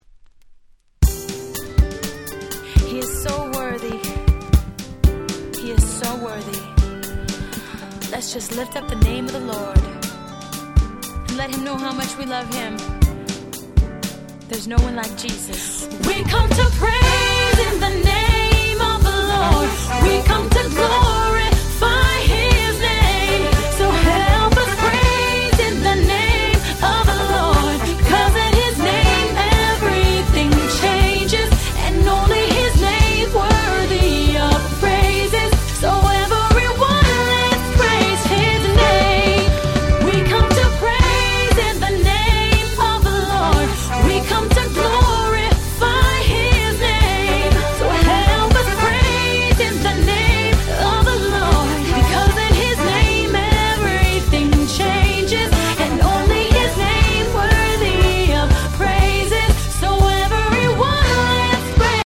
06' Nice R&B !!